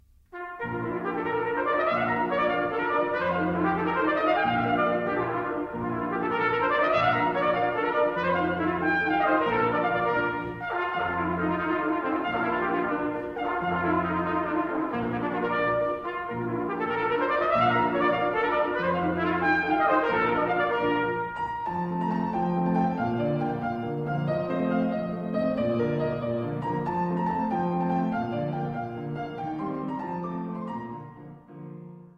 Piano.
cornet